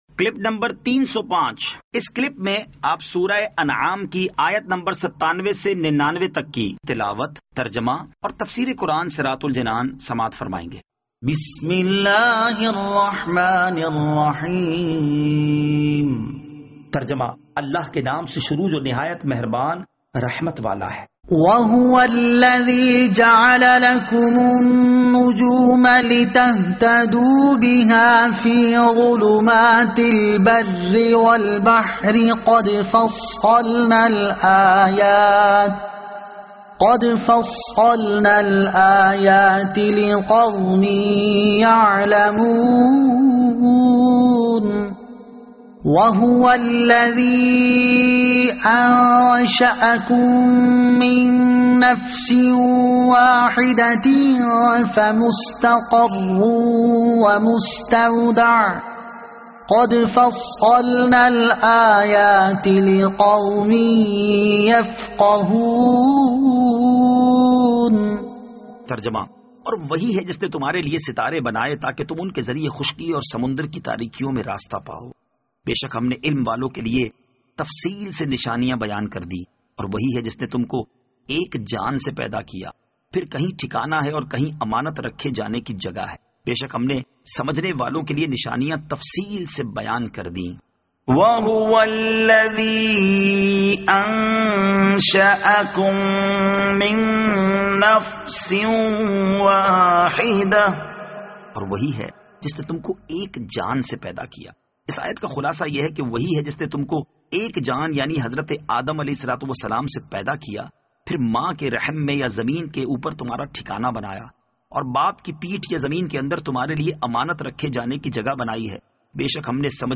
Surah Al-Anaam Ayat 97 To 99 Tilawat , Tarjama , Tafseer